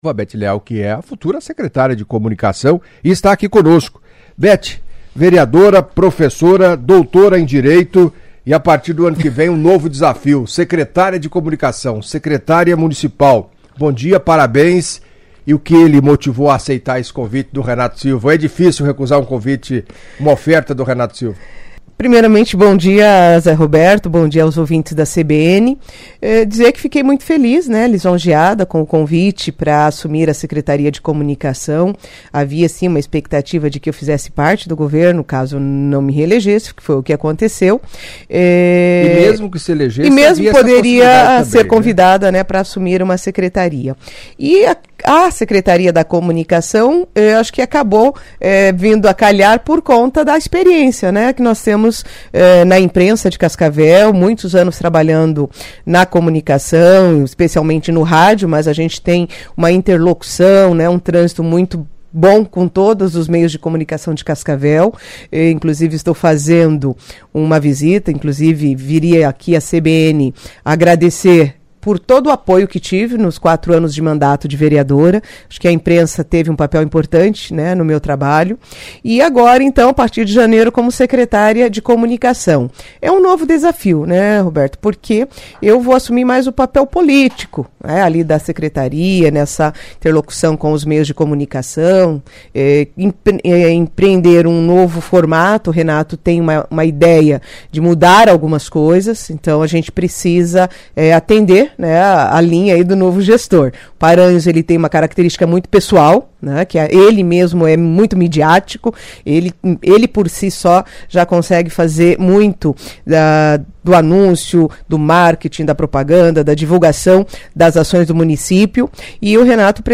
Em entrevista à CBN nesta terça-feira (10) Beth Leal, professora doutora em Direito, vereadora, anunciada pelo prefeito eleito Renato Silva como a secretária de Comunicação do município de Cascavel, a partir de janeiro, falou do convite e do nosso desafio.